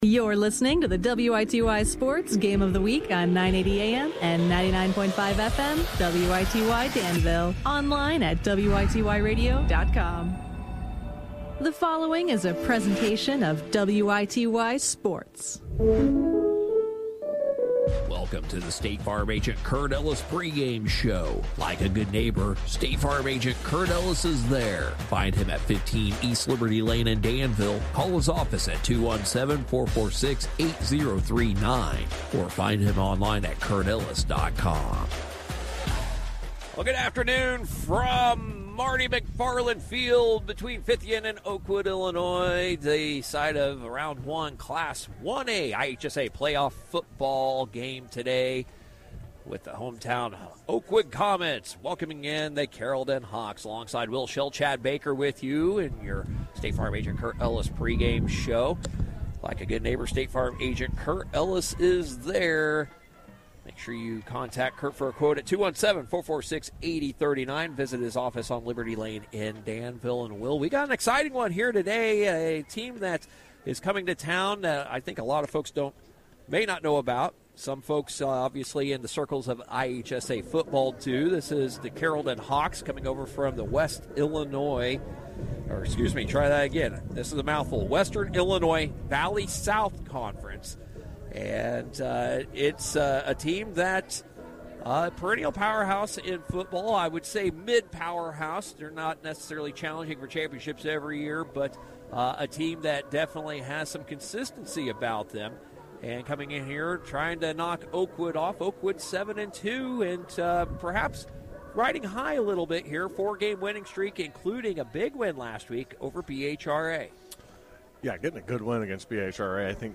Saturday November 1, 2025: Carrollton Hawks at Oakwood Comets (Round 1 of the Class 1A IHSA Football Playoffs)